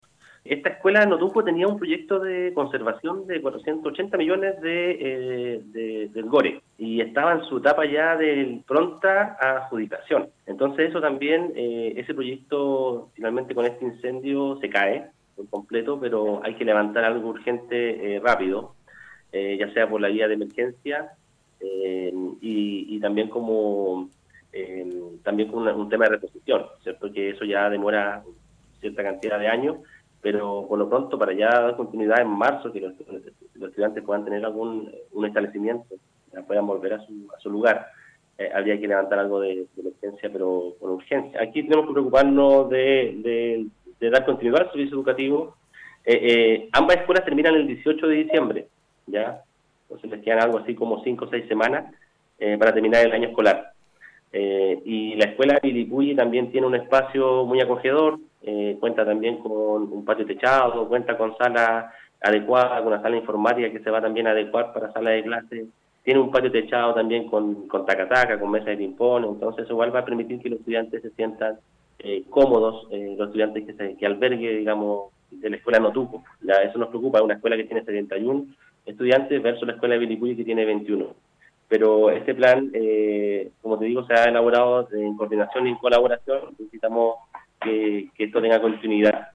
El seremi de Educación manifestó que se están haciendo gestiones para un proyecto de mediano plazo que permita reiniciar las clases en el mes de marzo de 2025.